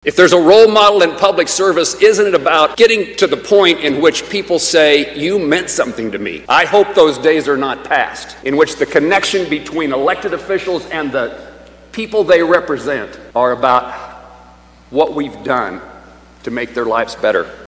Senator Jerry Moran says, in an emotion filled speech, Dole was not only beloved by his colleagues at the US Capitol, but the staff who worked there as well.